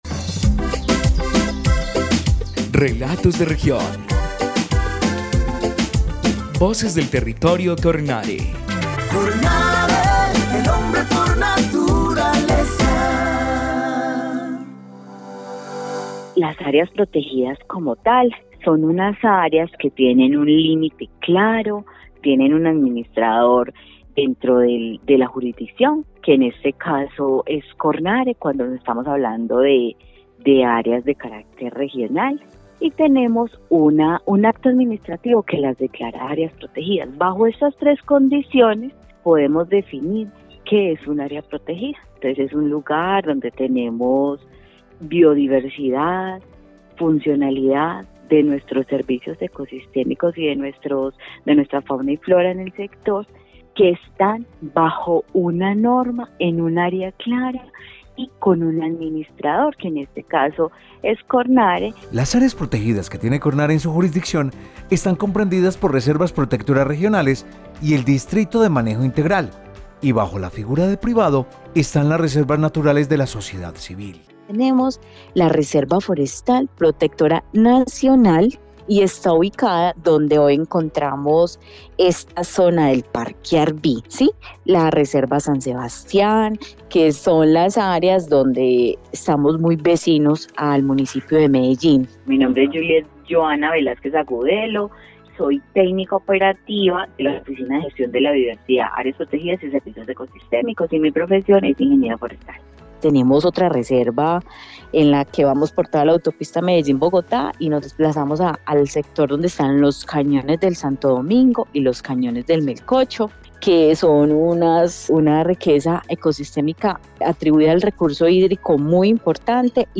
Programa de radio 2024